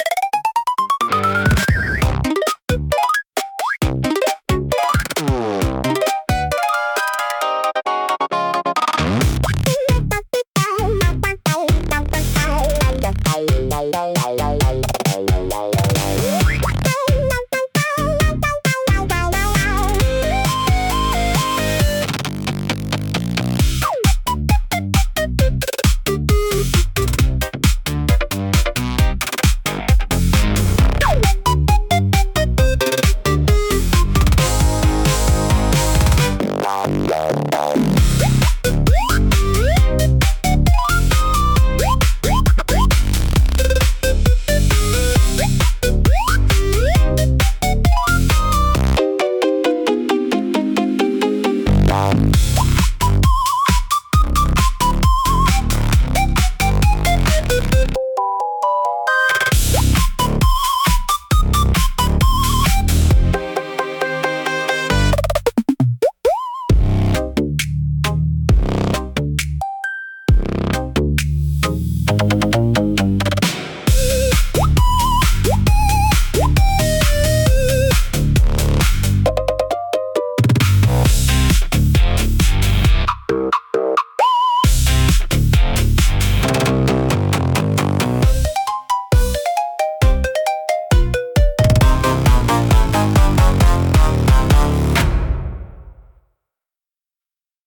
イメージ：インスト,アバンギャルド・ポップ,エレクトロニック・ポップ,グリッチ
インストゥルメンタル（instrumental）